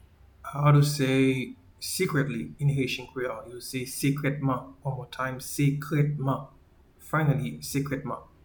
Pronunciation:
Secretly-in-Haitian-Creole-Sekretman.mp3